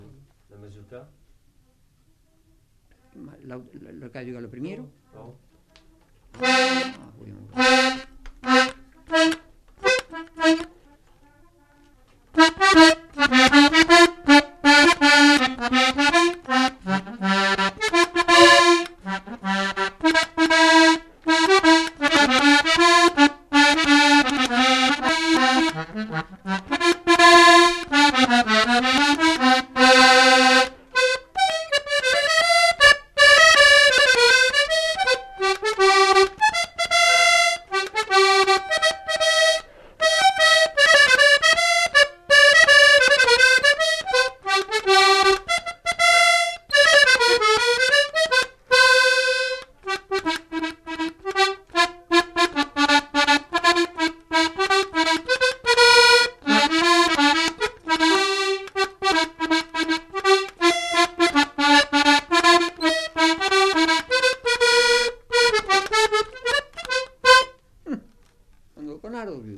Genre : morceau instrumental
Instrument de musique : accordéon diatonique
Danse : mazurka
Ecouter-voir : archives sonores en ligne